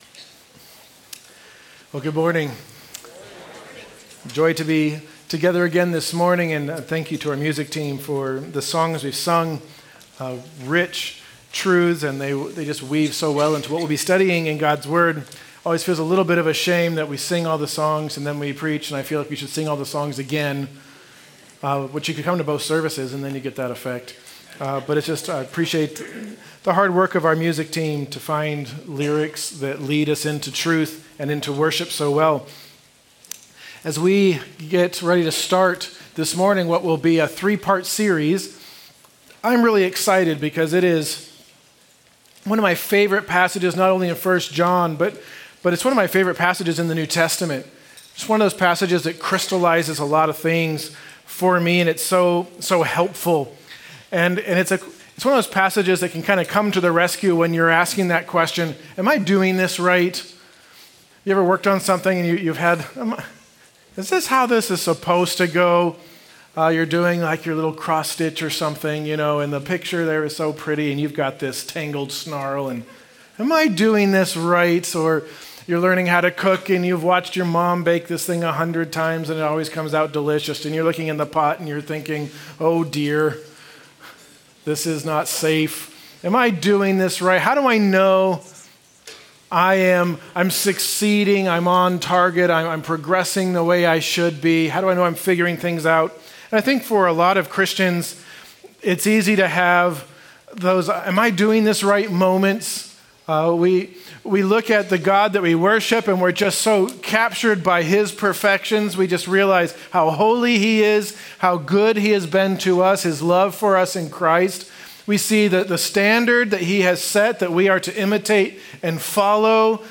March 8’s Sunday service livestream, bulletin/sermon notes/Life Group questions, the online Connection Card, and playlists of Sunday’s music (Spotify and YouTube).